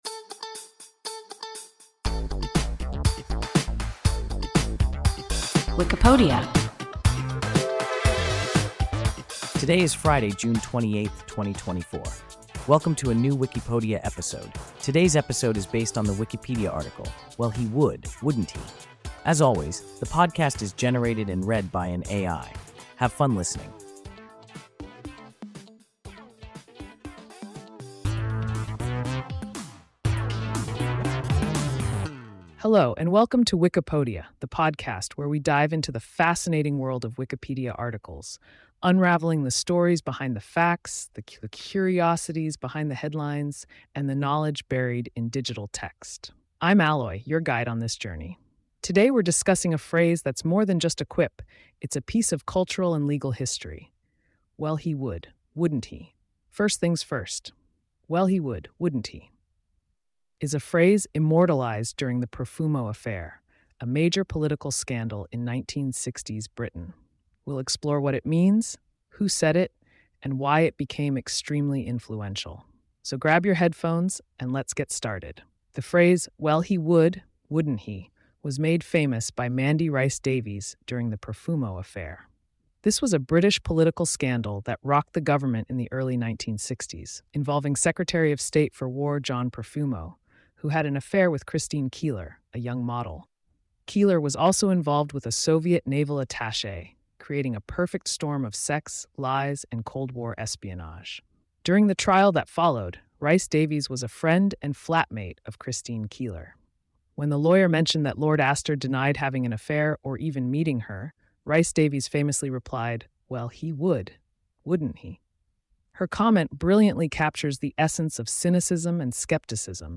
– WIKIPODIA – ein KI Podcast